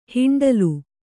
♪ hiṇḍalu